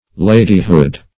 Search Result for " ladyhood" : The Collaborative International Dictionary of English v.0.48: Ladyhood \La"dy*hood\, n. The state or quality of being a lady; the personality of a lady.
ladyhood.mp3